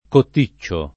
DOP: Dizionario di Ortografia e Pronunzia della lingua italiana
cotticcio